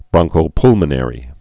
(brŏngkō-plmə-nĕrē, -pŭl-)